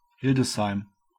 German pronunciation: [ˈhɪldəsˌhaɪm]